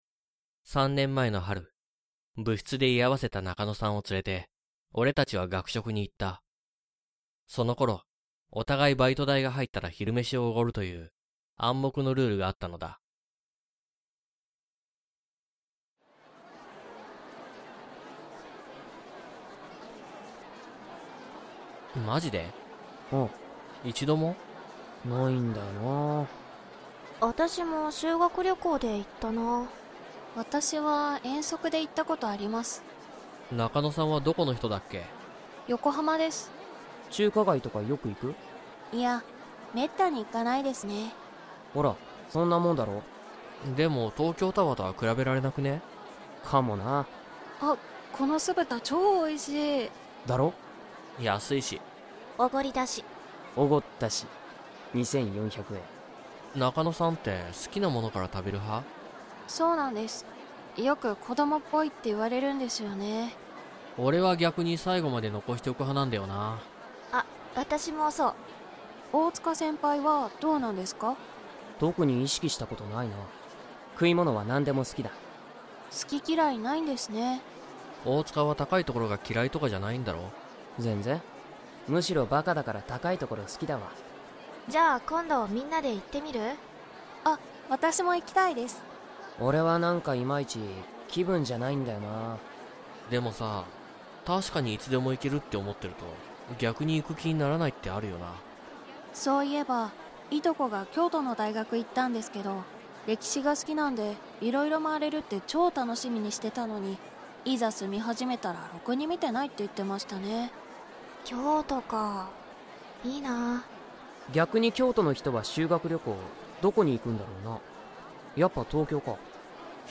オーディオドラマ「引越し前夜」